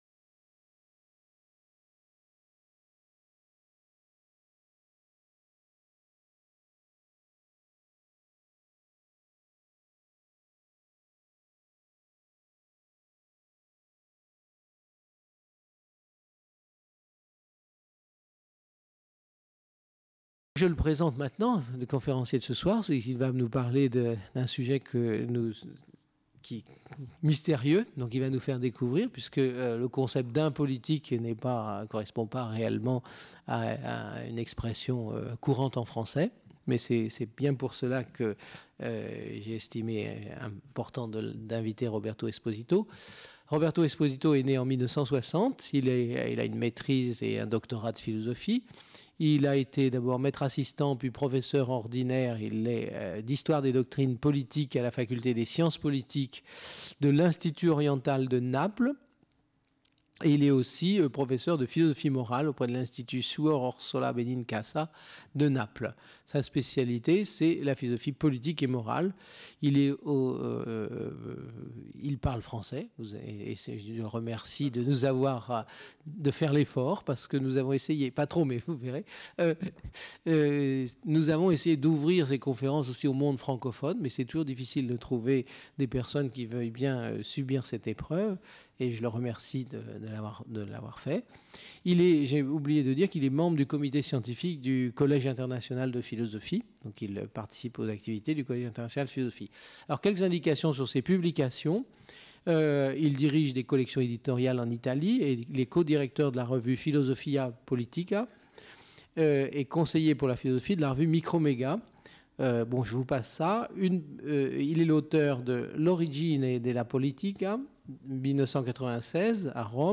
Conférence du 6 décembre 2000 par Robert Esposito.